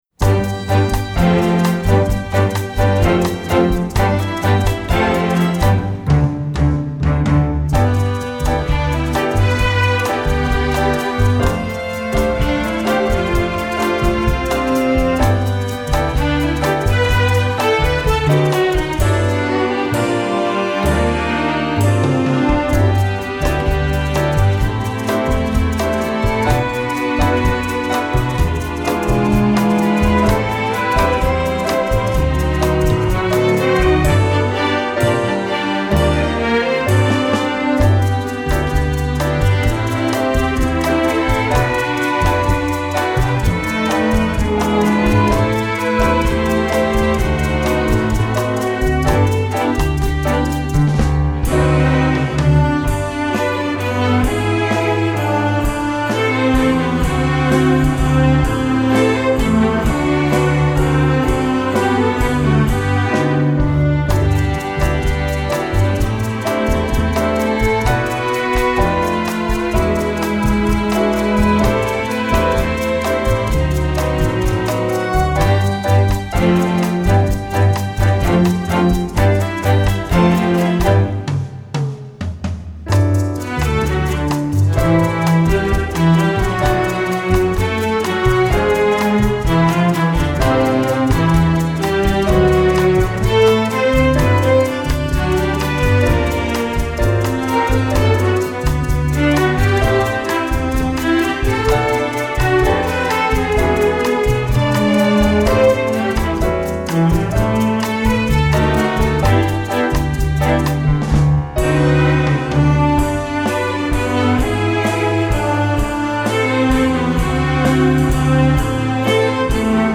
novelty, secular, traditional